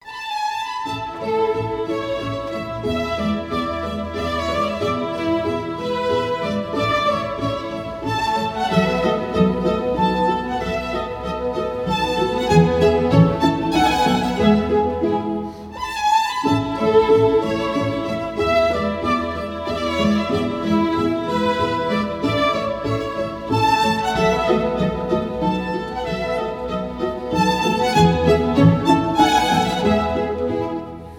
оркестр
скрипка
инструментальные